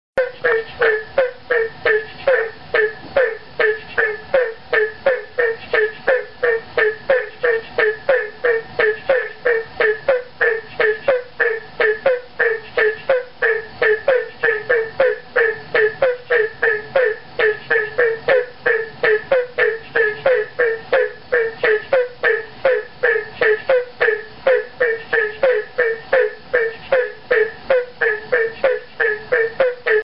Here's a lively chorus of Hyla gratiosa from Northeast Florida, and sadly the habitat from which this audio clip came has been completely destroyed, and lives on only here on Fabulous Froggage!